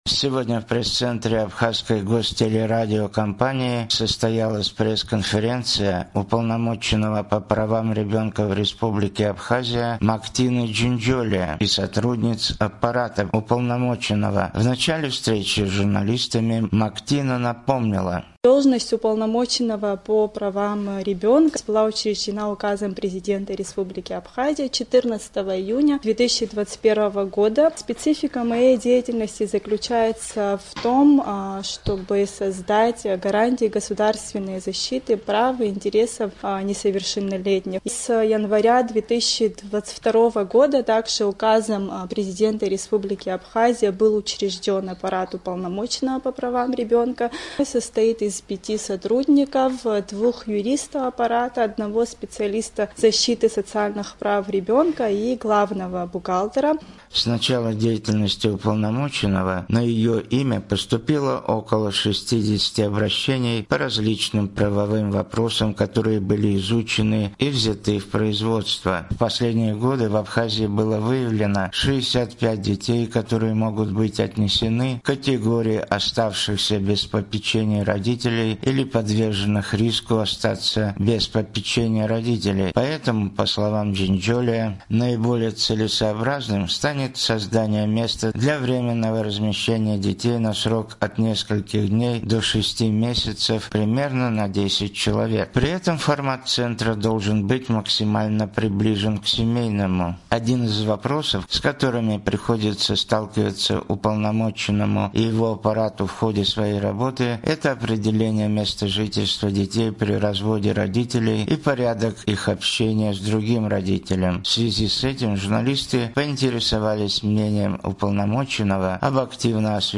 Сегодня в пресс-центре Абхазской гостелерадиокомпании состоялась пресс-конференция Уполномоченного по правам ребенка в Республике Абхазия Мактины Джинджолия и сотрудниц аппарата Уполномоченного.